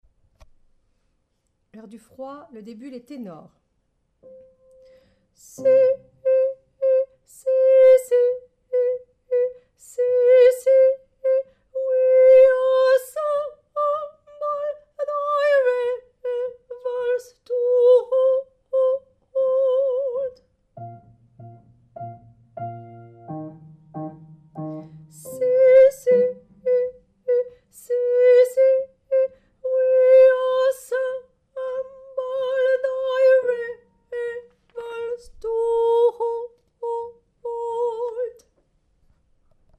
Ténor